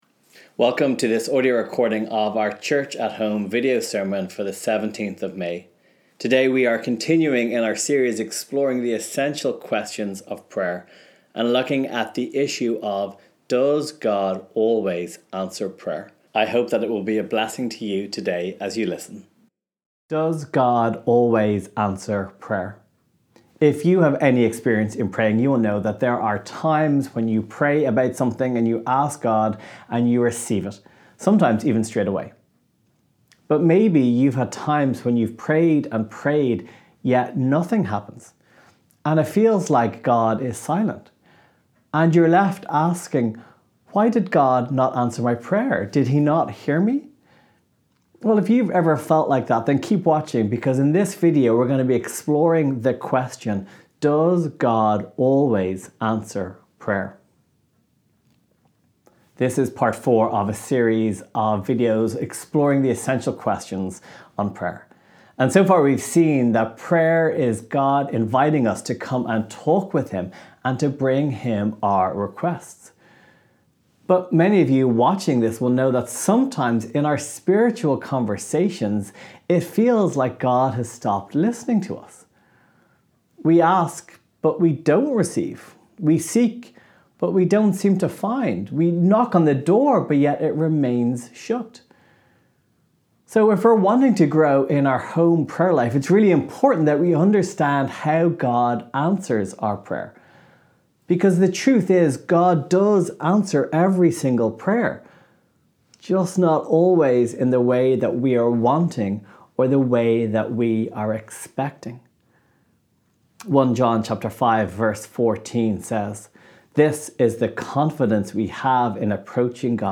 Sermon Library: Prayer Questions